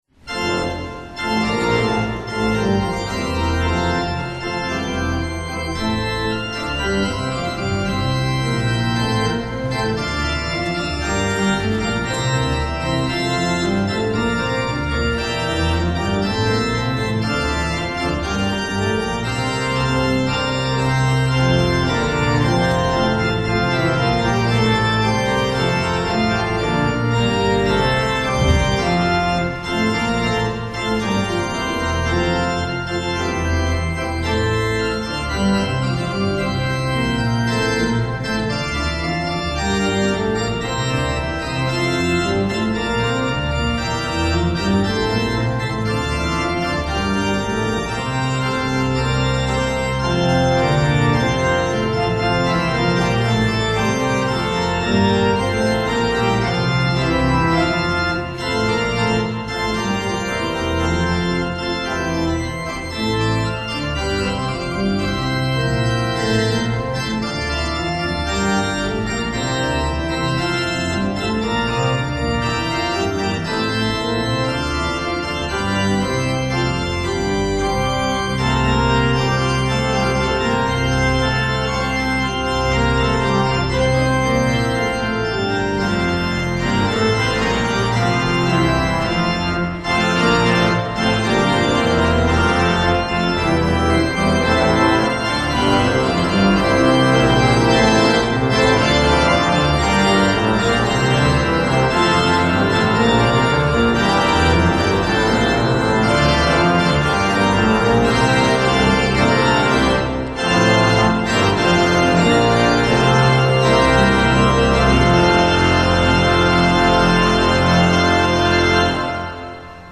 Hear the Bible Study from St. Paul's Lutheran Church in Des Peres, MO, from December 15, 2024.